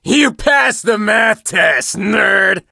monster_stu_kill_vo_03.ogg